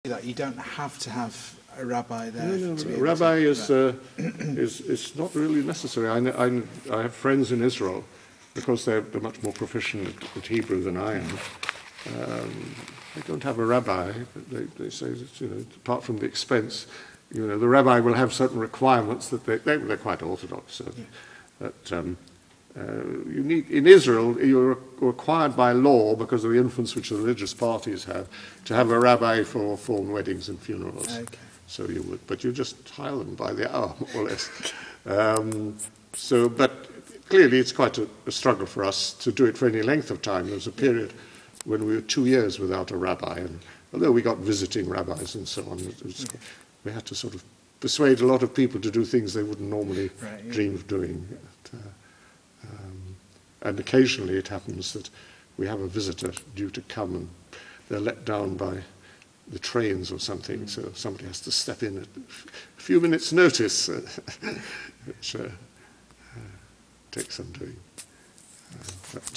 Judaism Audio Interviews
Here are a series of interview clips with an elder at a progressive Jewish synagogue in the United Kingdom.